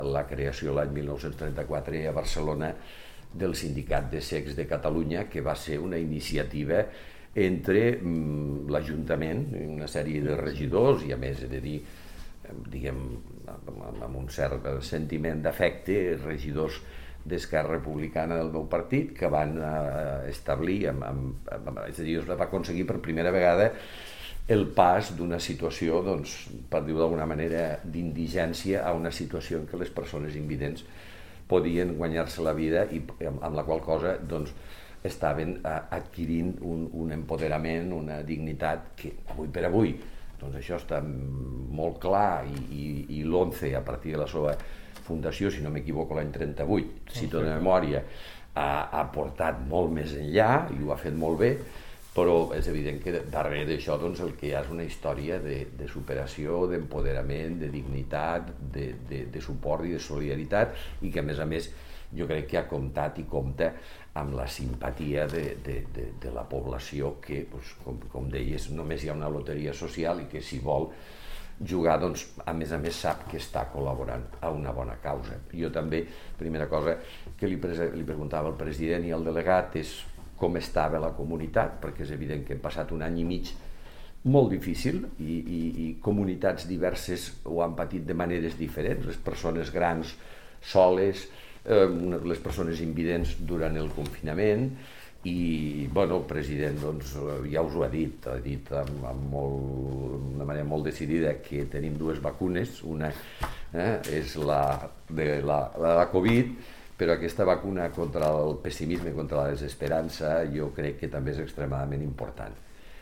tall-de-veu-de-lalcalde-miquel-pueyo-sobre-la-nova-aula-de-rehabilitacio-visual-de-lonce-a-lleida